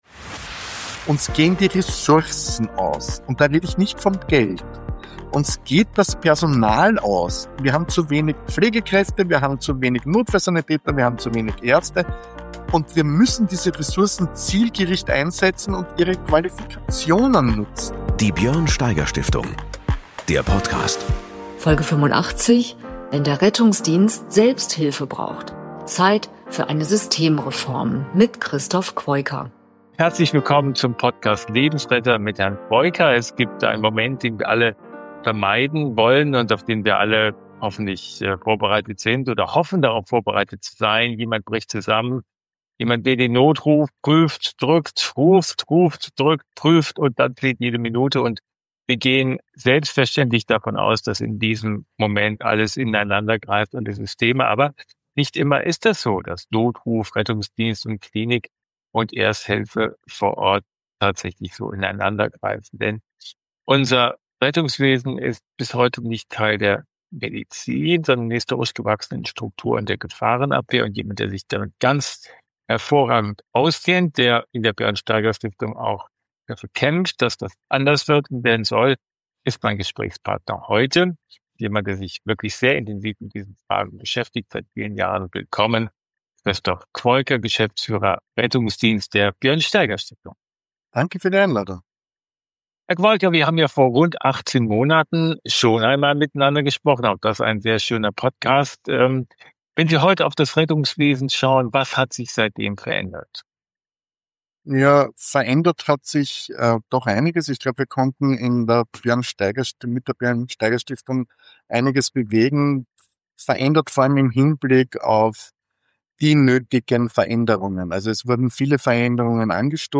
Ein Gespräch über Systemfehler, verpasste Chancen, internationale Vorbilder – und darüber, warum Deutschland beim Thema Notfallversorgung kein Erkenntnis-, sondern vor allem ein Umsetzungsproblem hat.